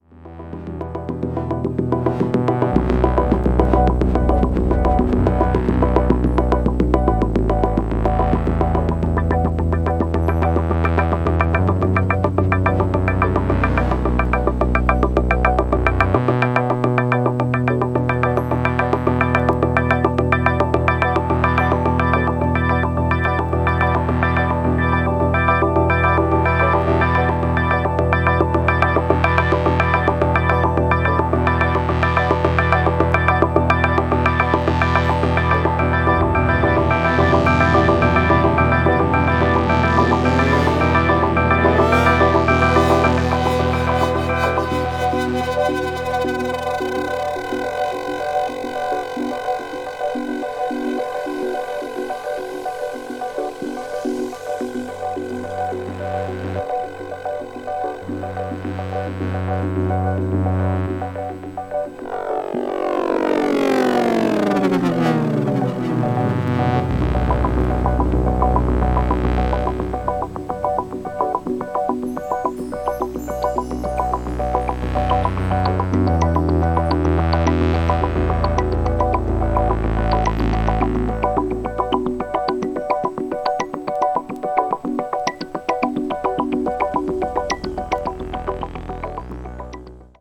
Super nice Electronic Music!